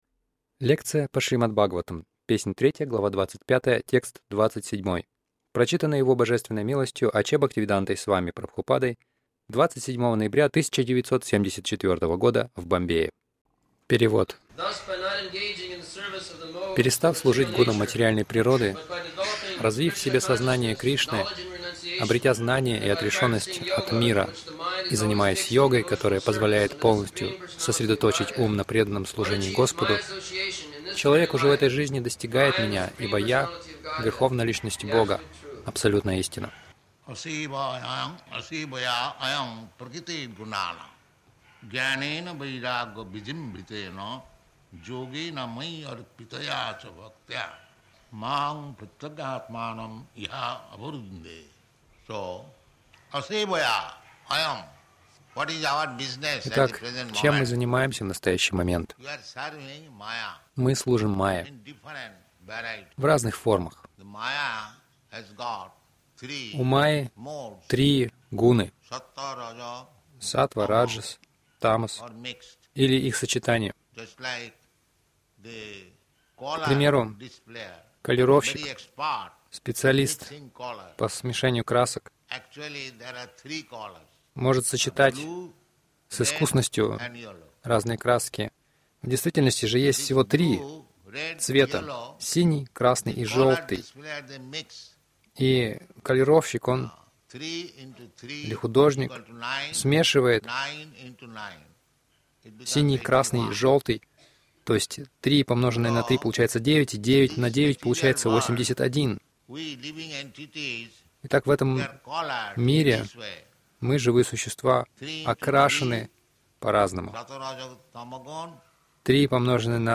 Милость Прабхупады Аудиолекции и книги 27.11.1974 Шримад Бхагаватам | Бомбей ШБ 03.25.27 — Служить Кришне или майе Загрузка...